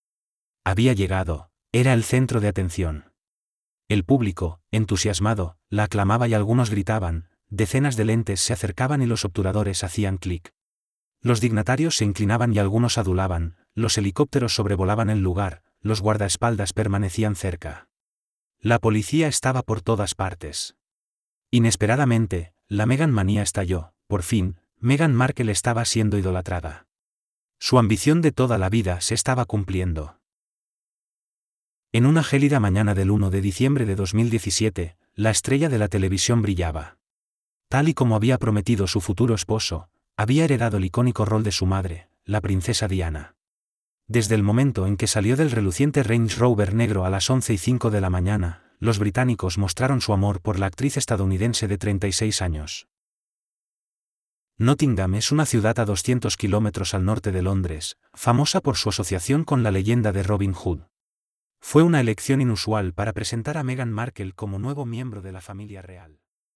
Utilizamos una poderosa herramienta de IA que es capaz de capturar todos los elementos presentes en la voz humana, como son el ritmo, la entonación y la calidad emocional.
Utilizamos voces neurales masculinas y femeninas tan reales que te costará distinguirlas.
audiolibro_voz_neural.wav